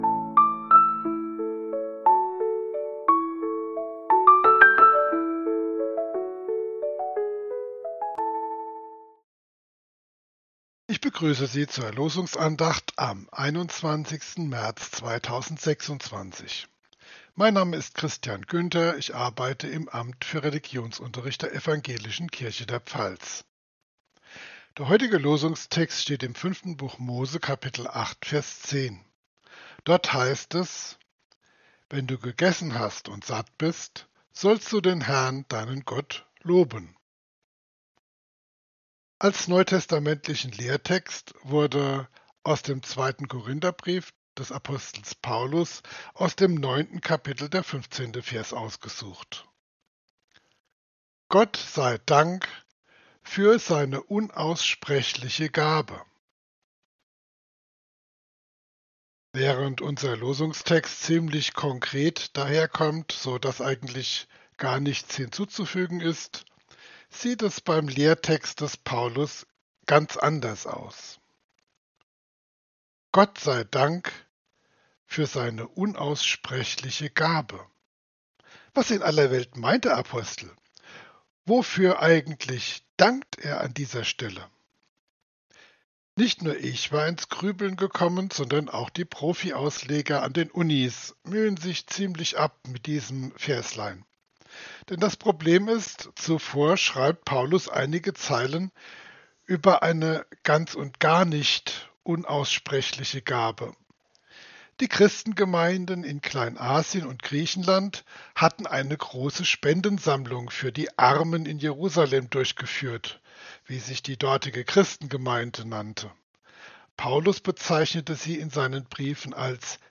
Losungsandacht für Samstag, 21.03.2026